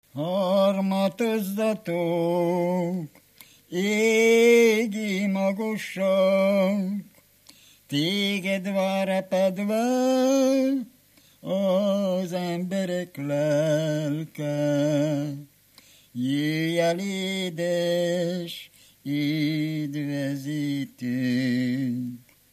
Moldva és Bukovina - Bukovina - Istensegíts
ének
Stílus: 4. Sirató stílusú dallamok
Kadencia: 4 (2) 2 1